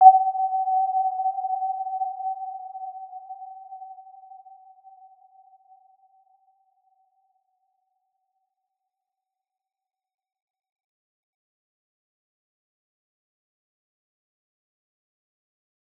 Warm-Bounce-G5-mf.wav